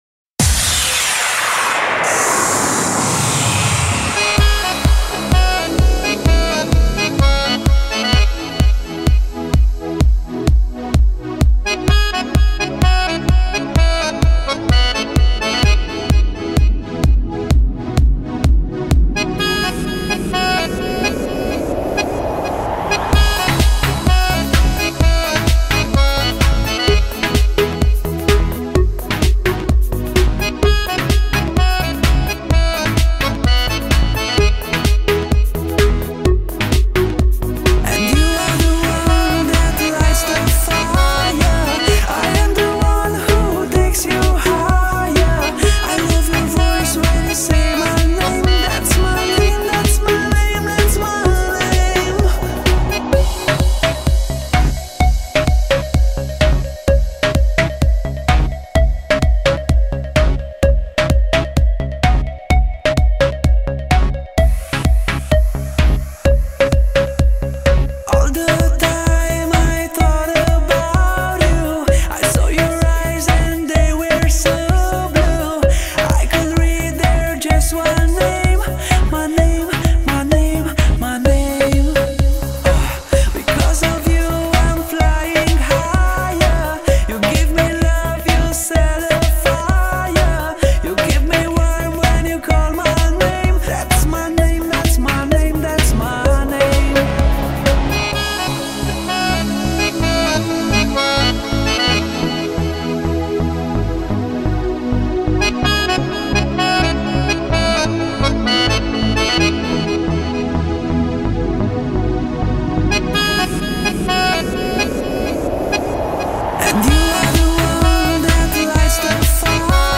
Genres: Dance/Electronic, Dance Pop